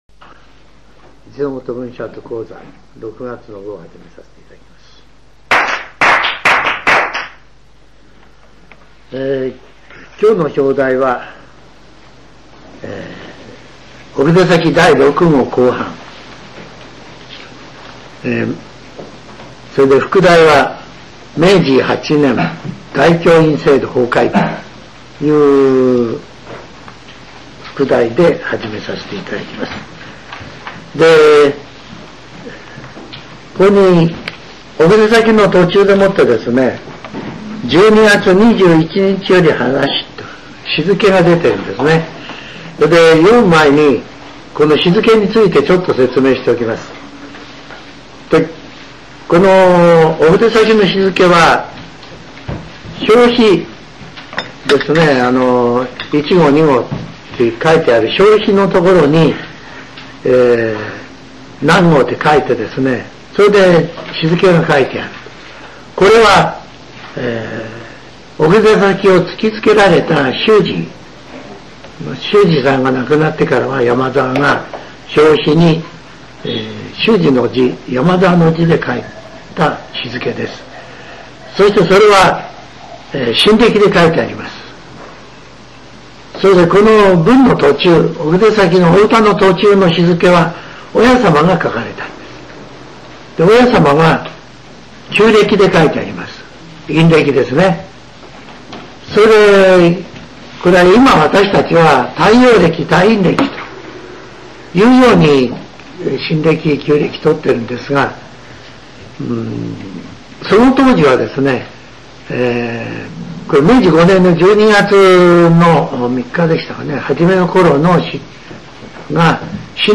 全70曲中61曲目 ジャンル: Speech